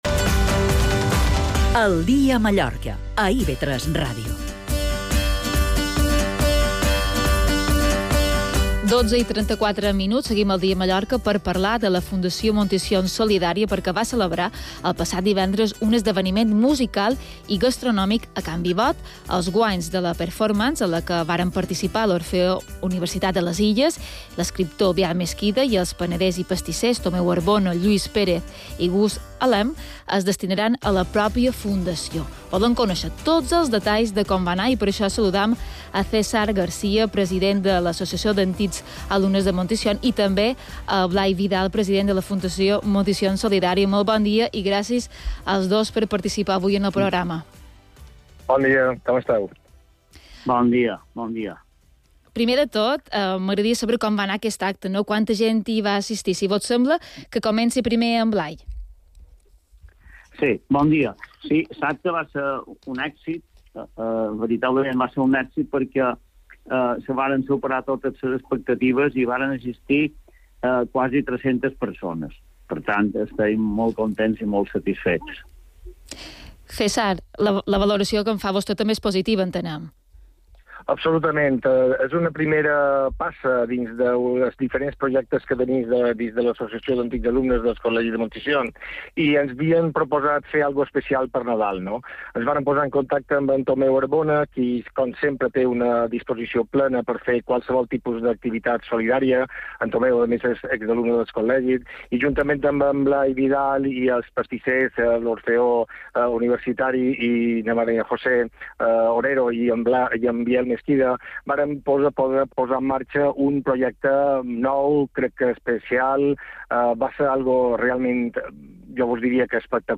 Mallorca_al_dia_Entrevista-.mp3